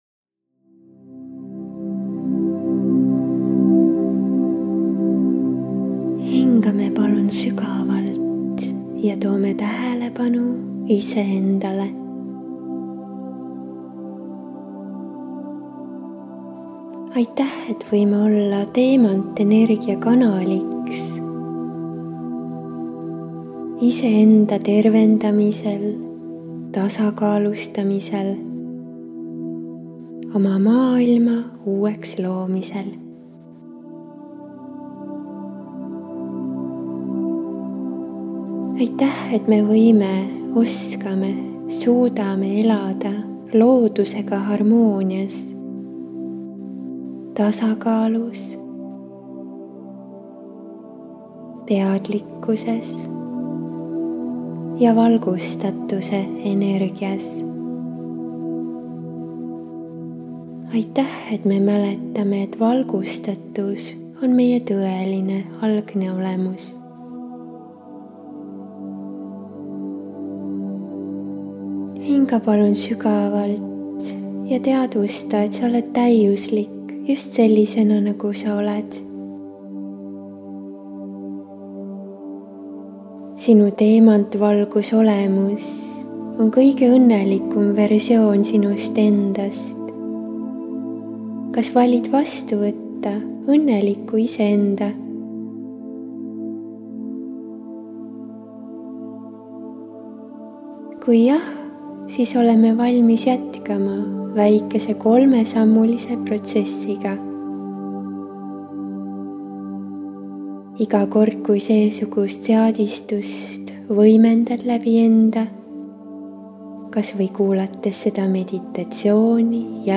12min LÜHIKE ENESE VÄESTAMISE MEDITATSIOON LAE TASUTA ALLA SIIT Soovitatav on kasutada seda seadistust iga päev.